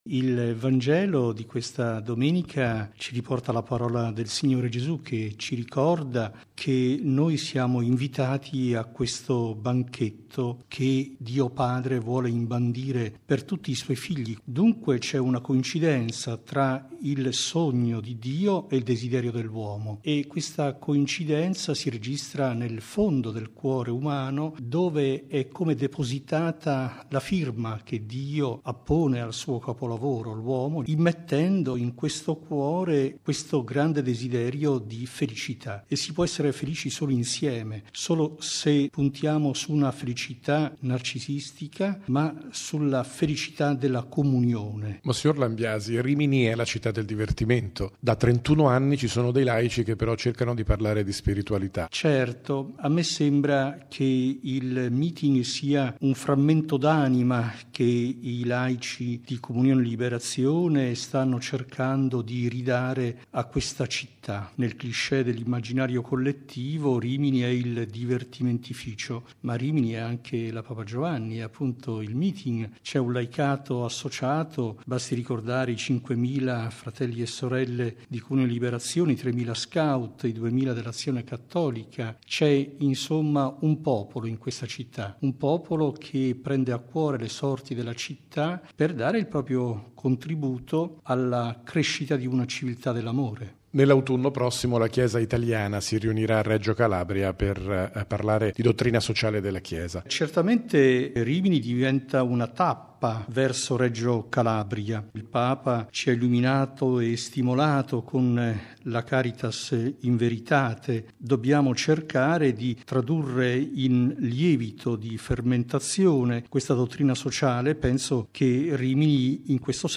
Il Meeting si è dunque aperto con la Messa celebrata dal vescovo di Rimini, mons. Francesco Lambiasi, che nella sua omelia ha messo l’accento sulla bellezza della relazione tra Dio e l’uomo. Un tema su cui il presule si sofferma nell’intervista